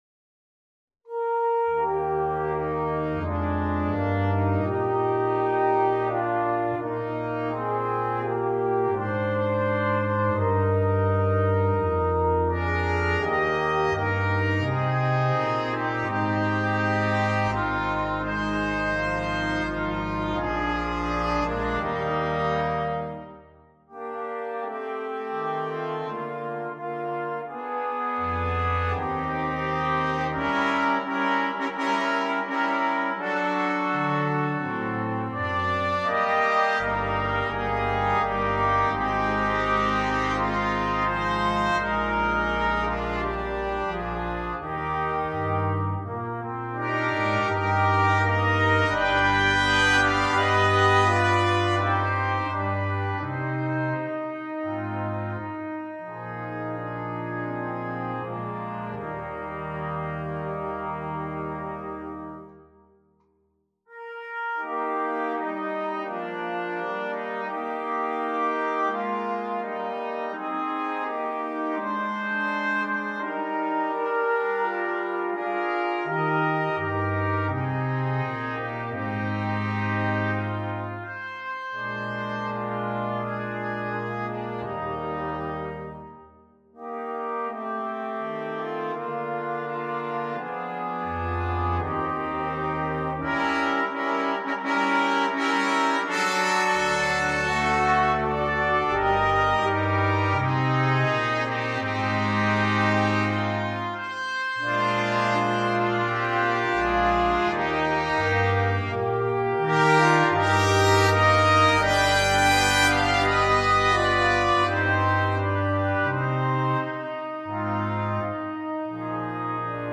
sans instrument solo
Classique
Partie 1: Trompette sib, Cornet à Pistons sib
Partie 3: Cor d’harmonie
Partie 4: Trombone – Clé de fa
Partie 5: Tuba